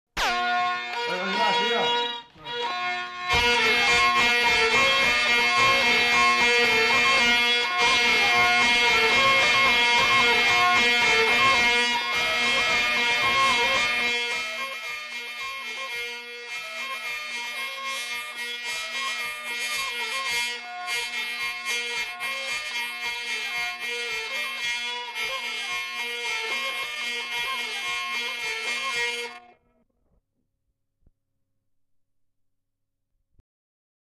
Genre : morceau instrumental
Instrument de musique : vielle à roue
Danse : rondeau